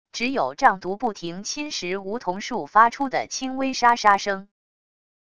只有瘴毒不停侵蚀梧桐树发出的轻微沙沙声wav音频